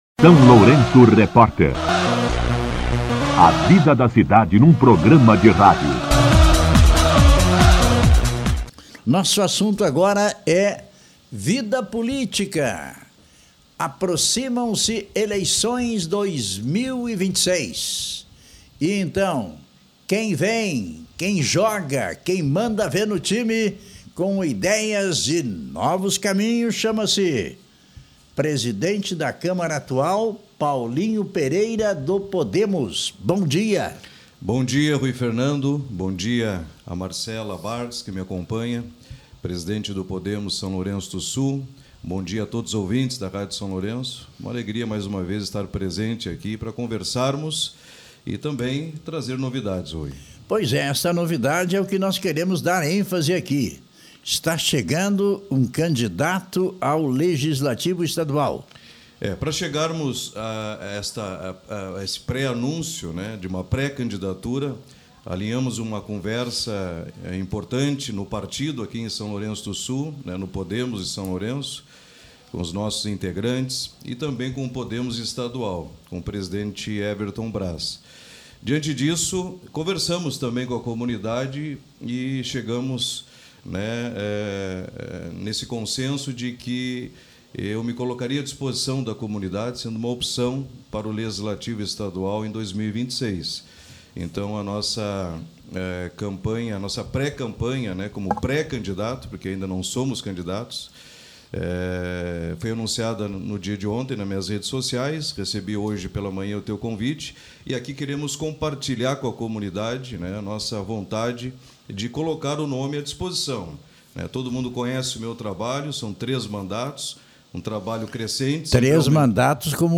Em entrevista exclusiva ao SLR Rádio, o vereador Paulinho Pereira (Podemos) oficializou sua pré-candidatura a deputado estadual nas eleições de 2026.